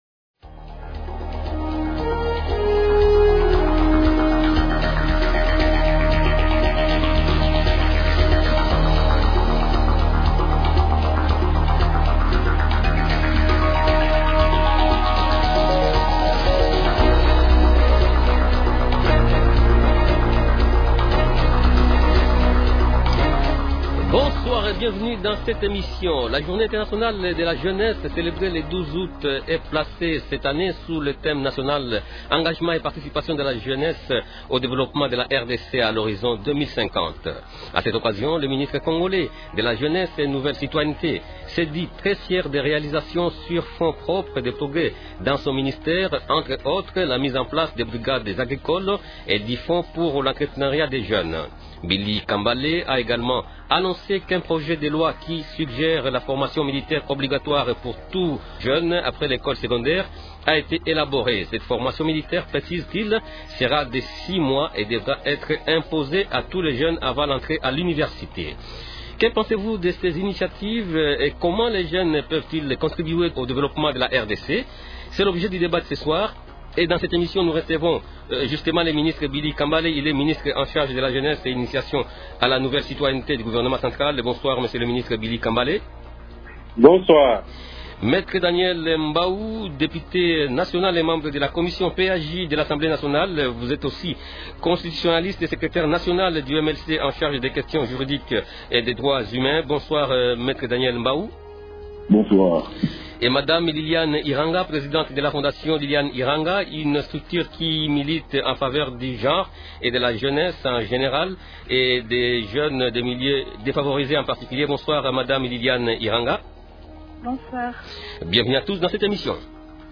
Invités Billy Kambale, Ministre en charge de la jeunesse et initiation à la nouvelle citoyenneté.